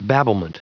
Prononciation du mot babblement en anglais (fichier audio)
Prononciation du mot : babblement